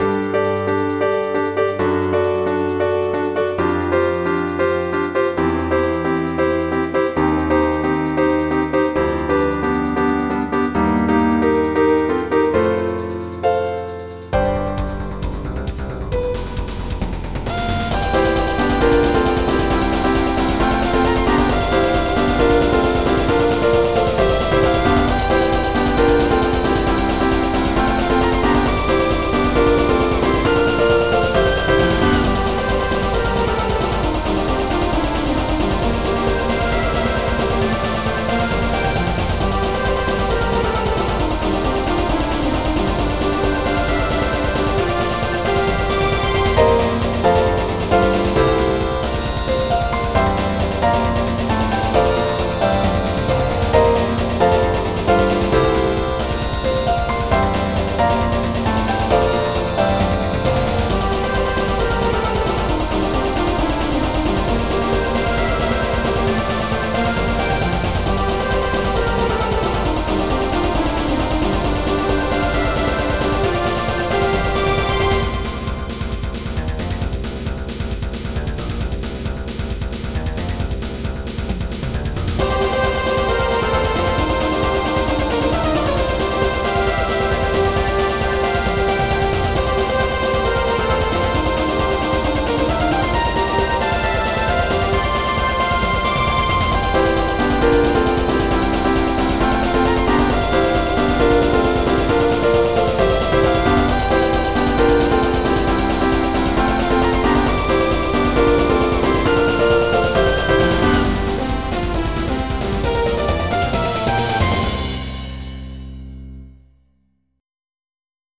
MIDI Music File
ASIAN.mp3